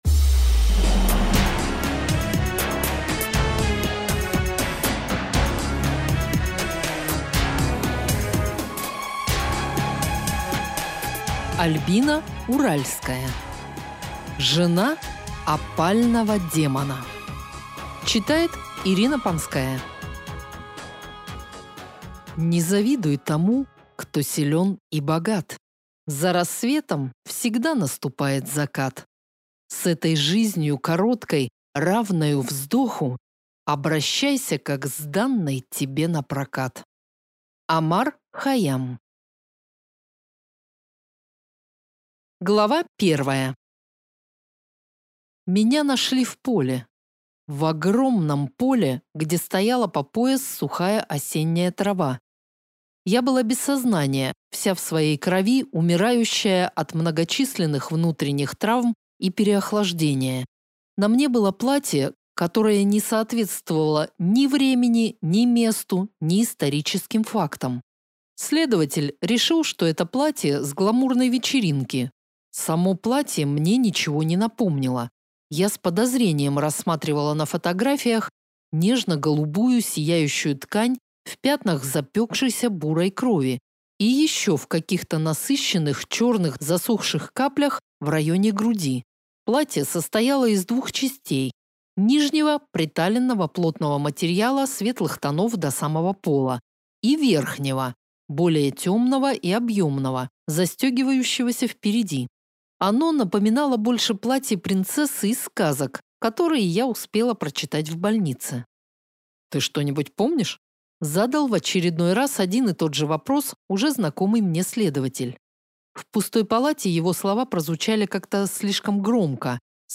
Аудиокнига Жена опального демона | Библиотека аудиокниг